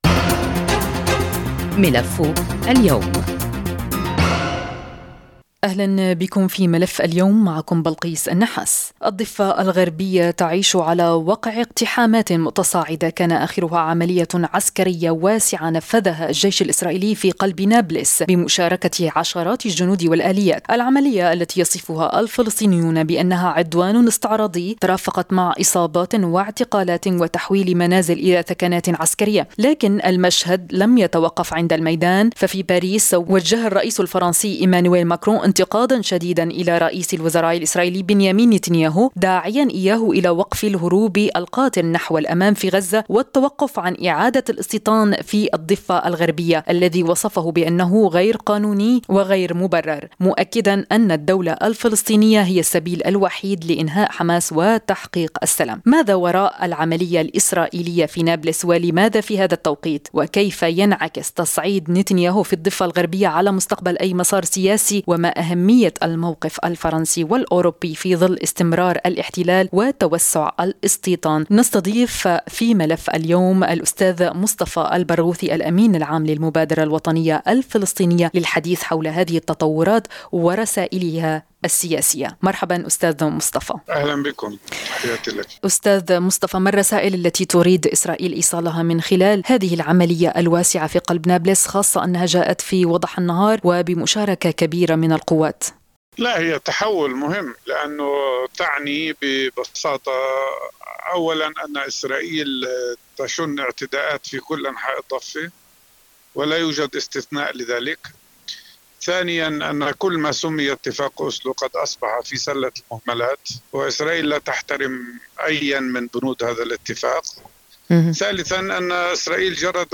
للحديث حول هذه التطورات ورسائلها السياسية، نستضيف في ملف اليوم الأستاذ مصطفى البرغوثي، الأمين العام للمبادرة الوطنية الفلسطينية.